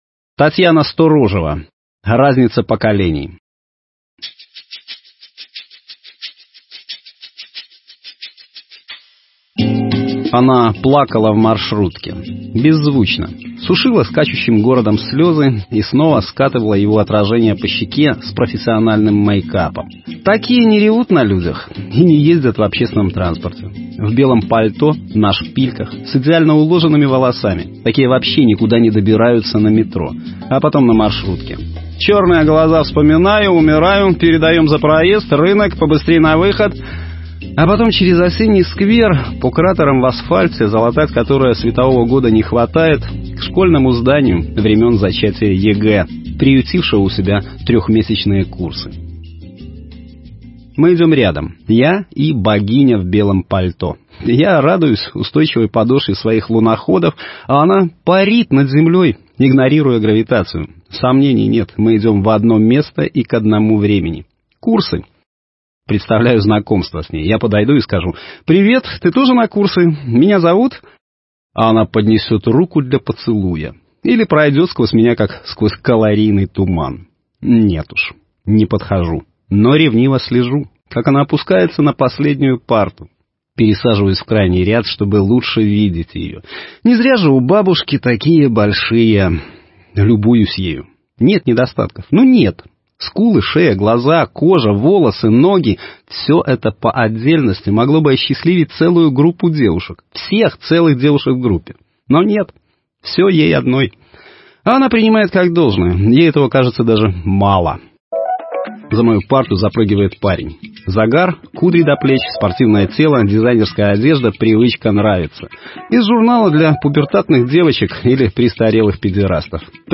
Аудио-Рассказы